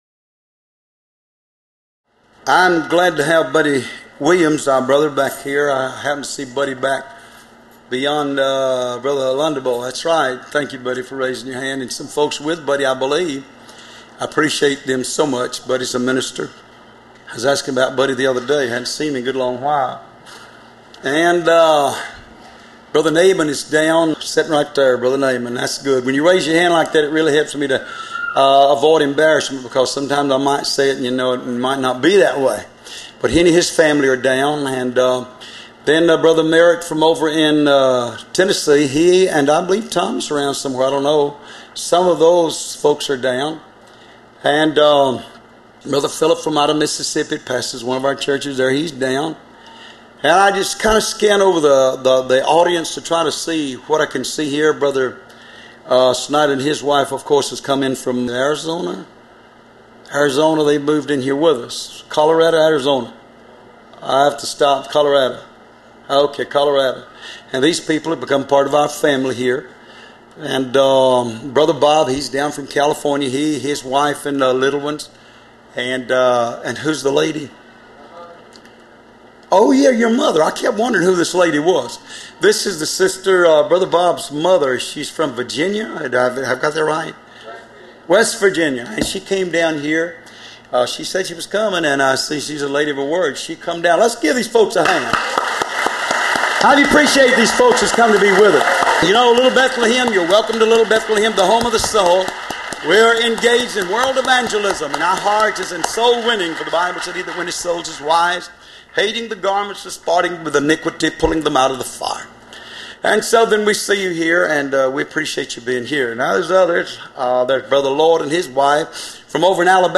Location: Love’s Temple in Monroe, GA USA
Sermons